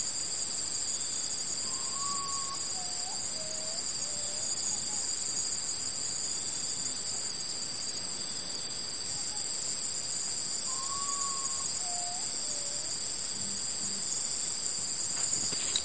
Urutau (Nyctibius griseus)
Nome em Inglês: Common Potoo
Localidade ou área protegida: Las Varillas
Condição: Selvagem
Certeza: Gravado Vocal
urutau.mp3